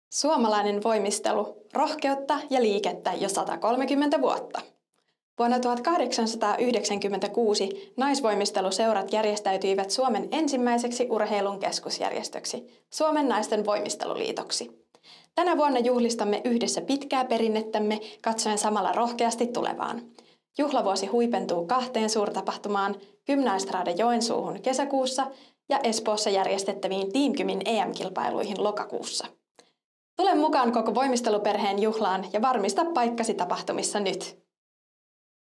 130v juhlavuosi äänimainos - Materiaalipankki
130v-juhlavuosi-aanimainos.mp3